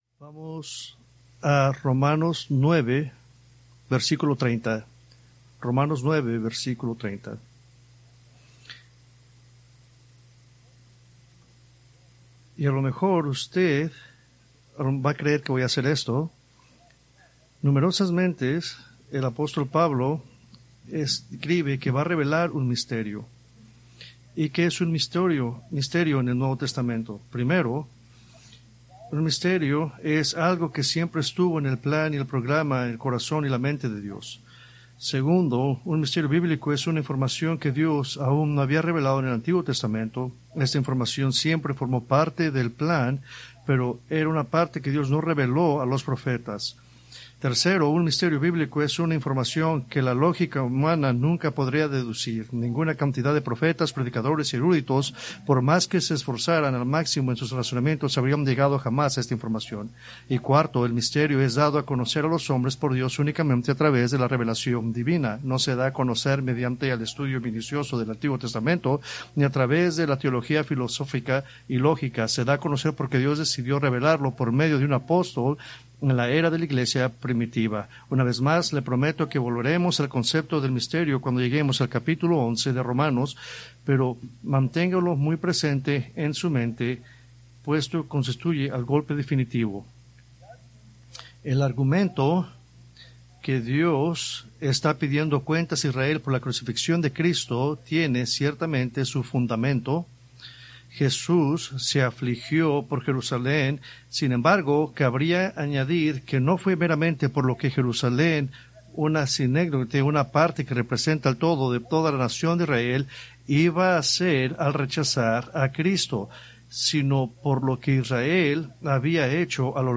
Preached April 18, 2026 from Romanos 9:30-10:21